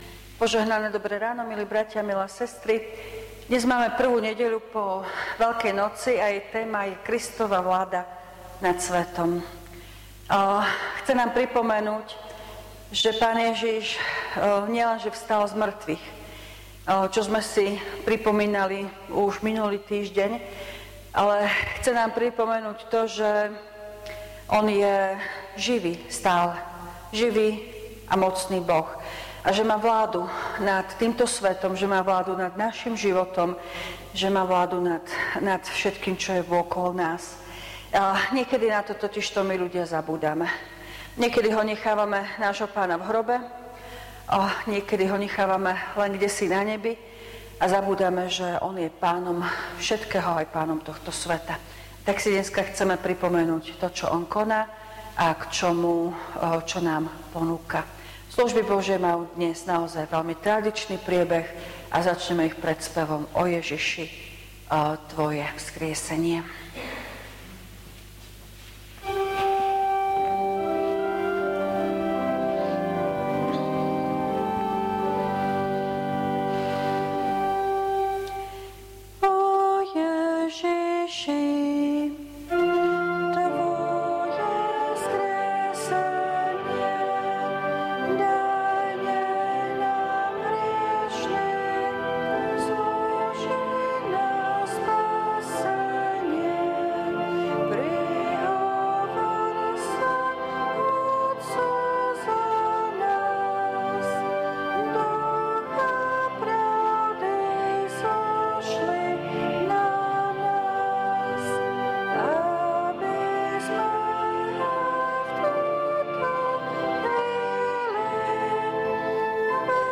V nasledovnom článku si môžete vypočuť zvukový záznam zo služieb Božích – 1. nedeľa po Veľkej noci.